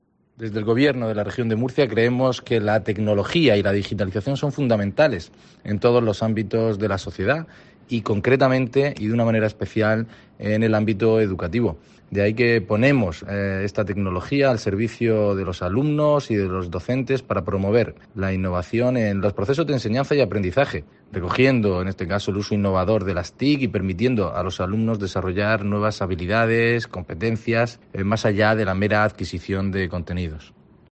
Juan García Iborra, director general de Formación Profesional e Innovación